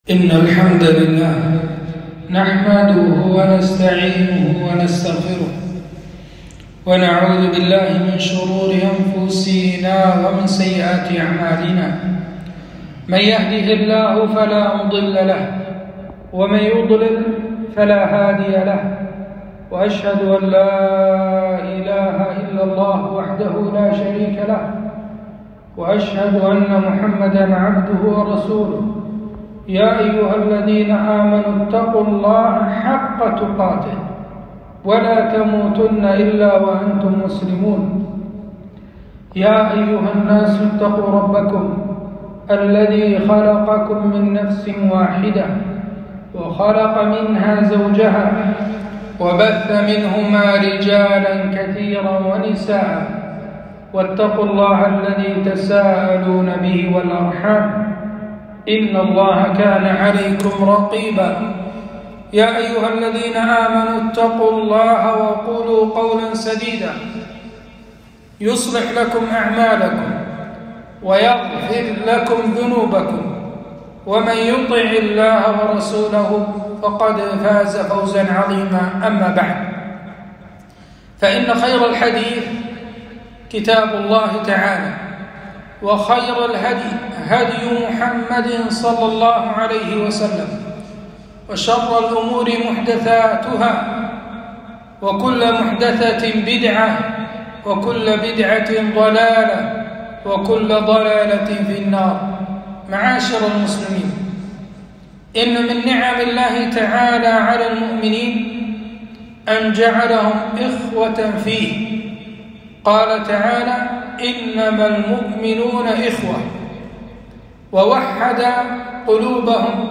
خطبة - المسلم أخو المسلم